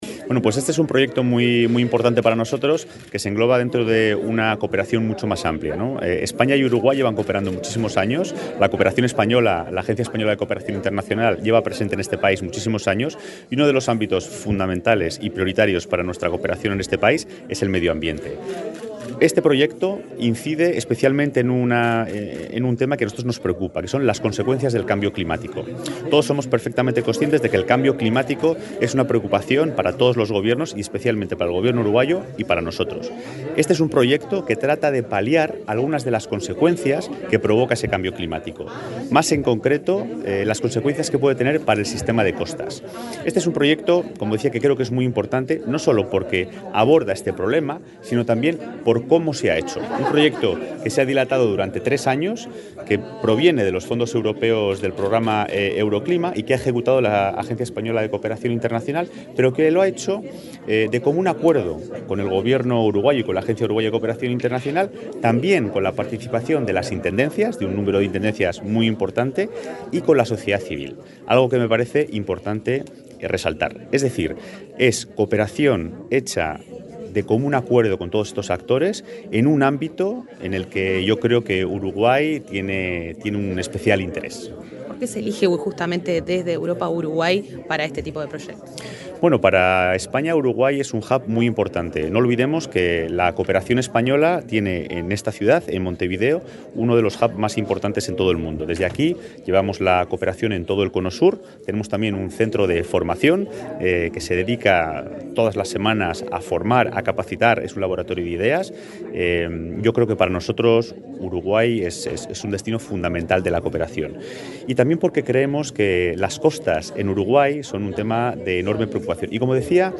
Entrevista al embajador de España, Santiago Jiménez Martín
El embajador de España en Uruguay, Santiago Jiménez Martín, dialogó con Comunicación Presidencial en Torre Ejecutiva, antes de la presentación de